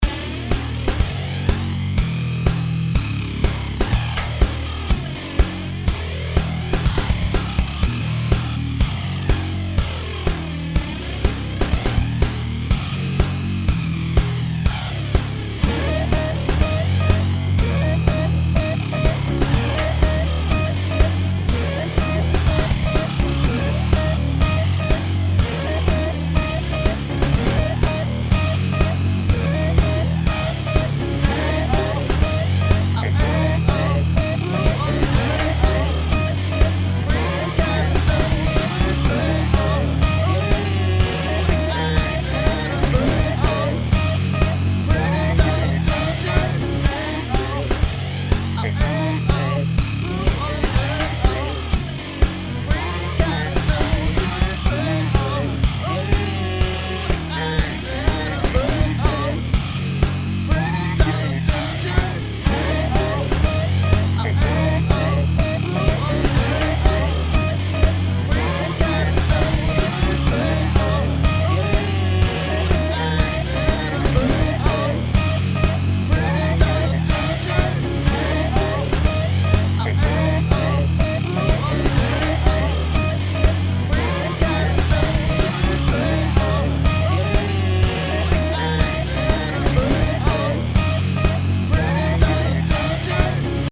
home of the daily improvised booty and machines -
bass grooves